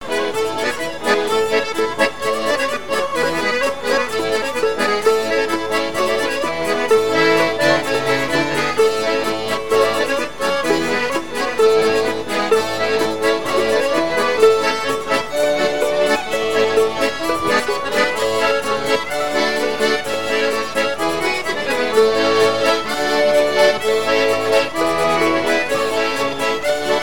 danse : polka
partie de répertoire de Sounurs pour un bal
Pièce musicale inédite